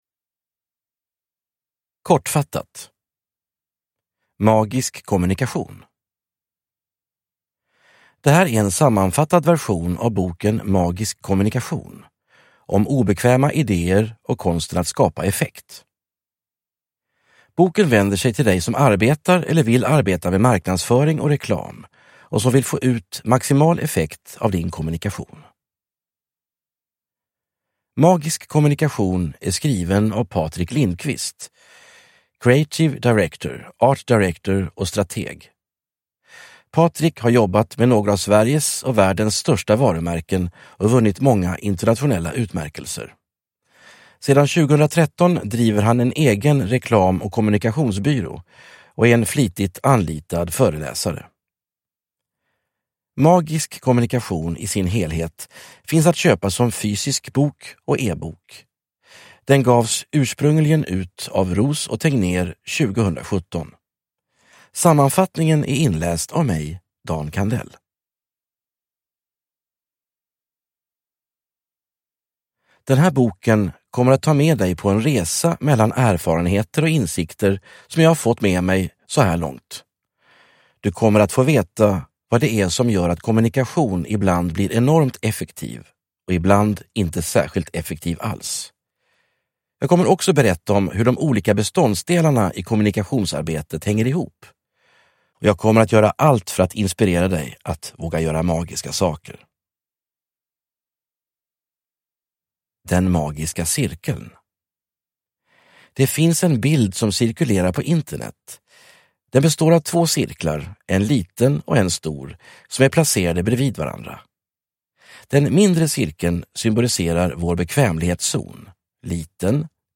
Magisk kommunikation – Ljudbok – Laddas ner